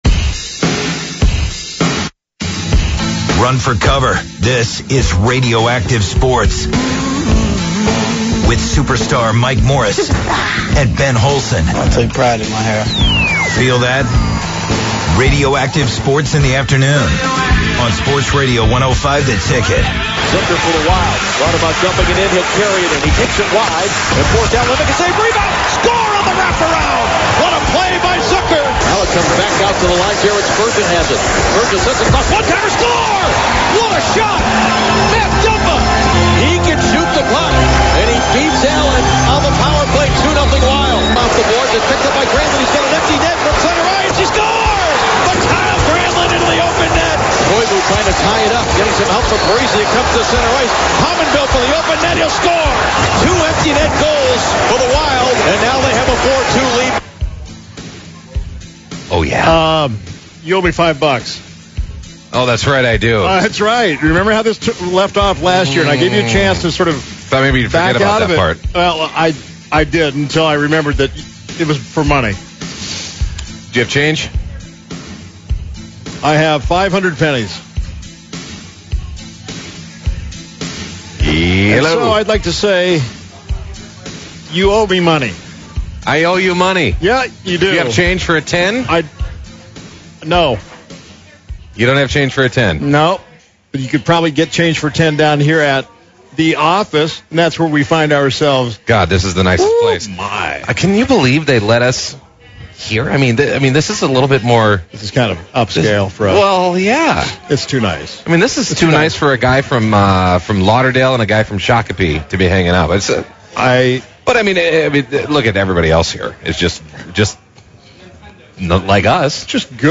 on location for Twins Ticket Friday